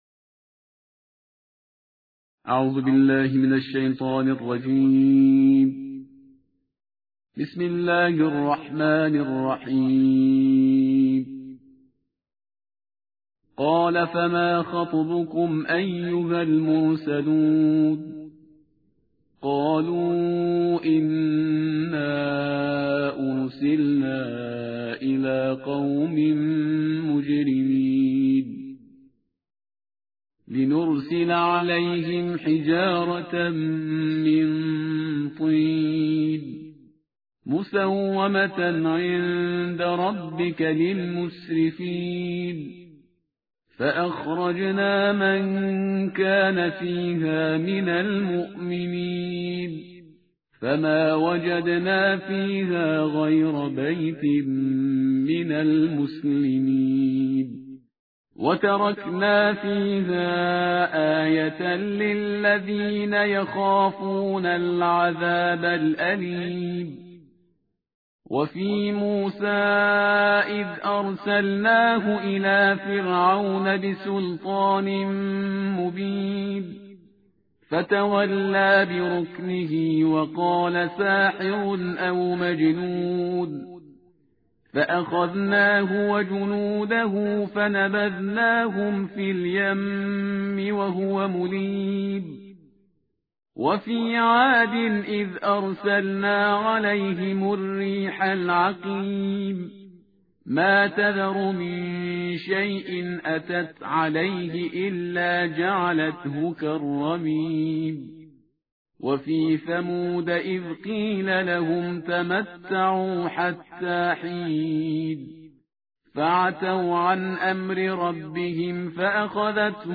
ترتیل جزء بیست و هفت قرآن کریم/استاد پرهیزگار